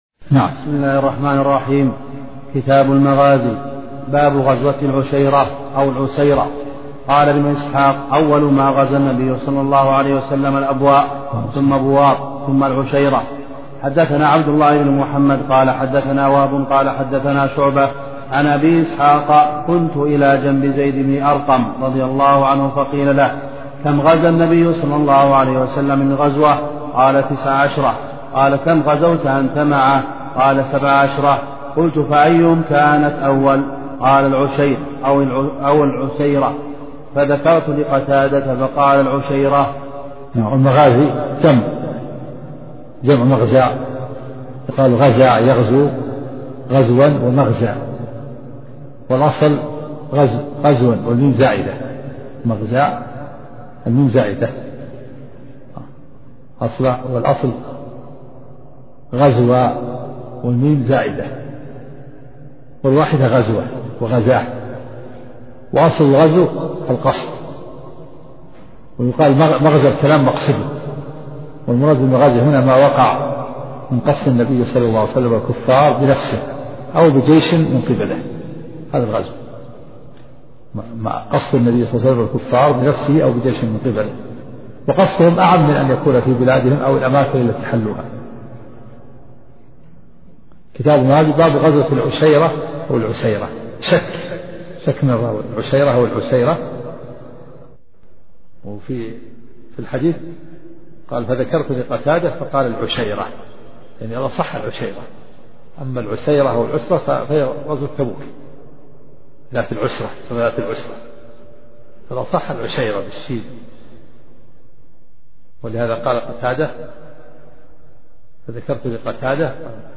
سلسلة محاضرات صوتية